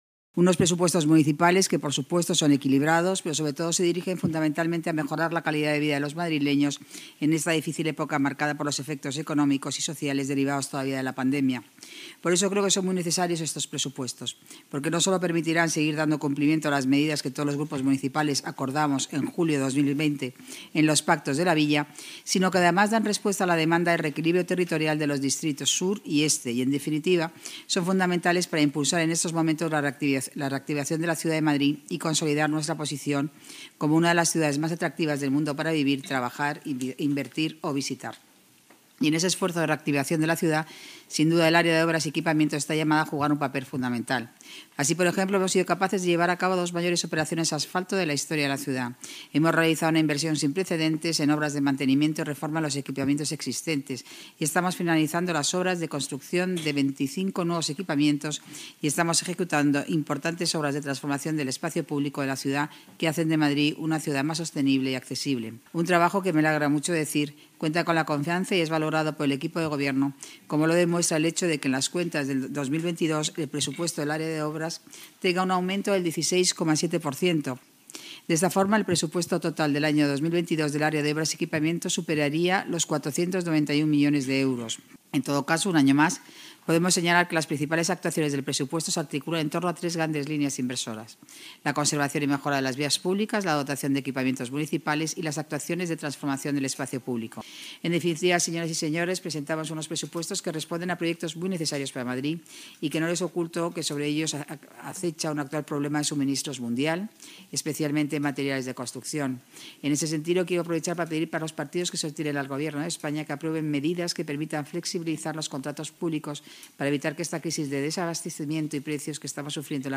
La delegada de Obras y Equipamientos, Paloma García Romero, ha comparecido hoy en la Comisión Permanente de Hacienda y Personal y de Economía, Innovación y Empleo para informar sobre el presupuesto de su área para el año 2022, que ascenderá a más de 491 millones de euros, lo que supone un aumento del 16,7 % respecto al ejercicio anterior.